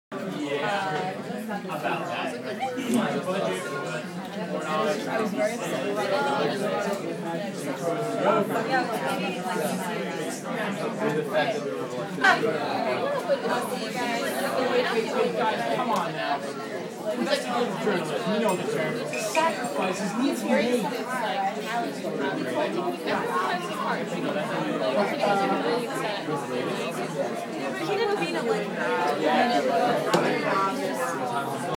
Banging Tables – Hofstra Drama 20 – Sound for the Theatre
Field Recording #1
Sounds in Clip : Various levels of talking/chatter from various distances, phone dropping on table, bags being dropped on chair, hollow bottle being dropped on table, clanking and moving of chairs